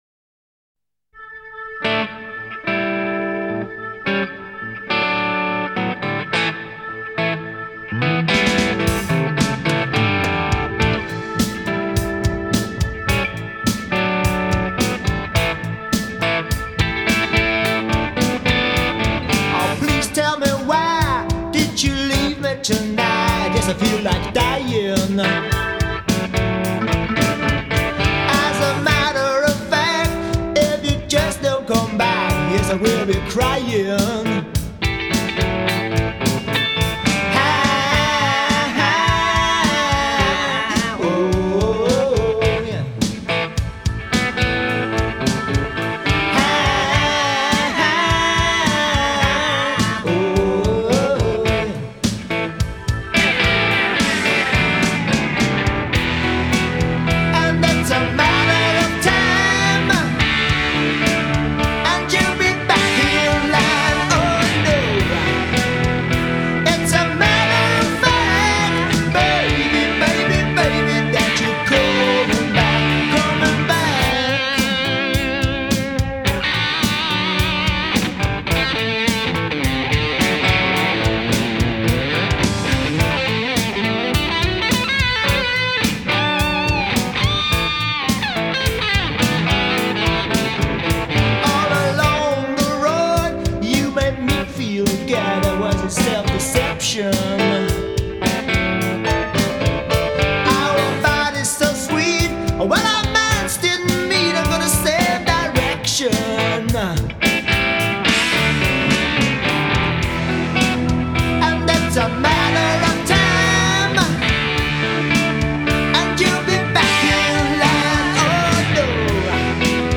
Rock'n'roll - das tut wohl
Gesang und Gitarre
Synthi
drums.